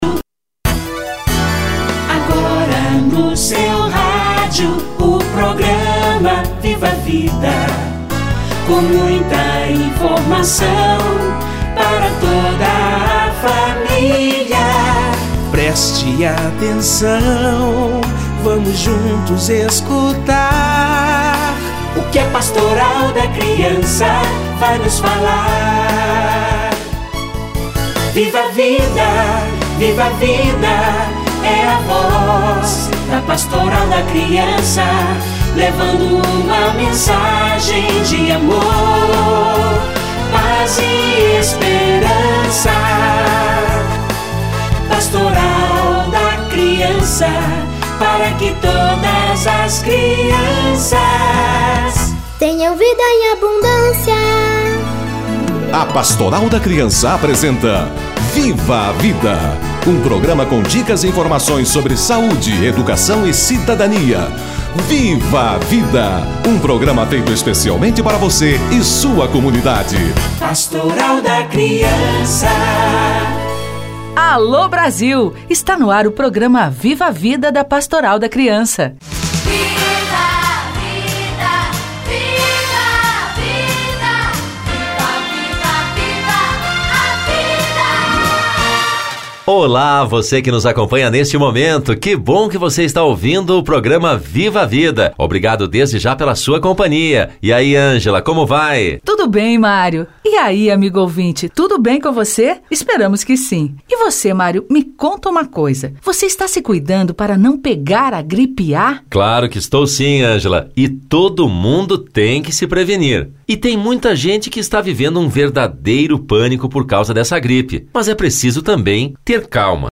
Gripe A - Entrevista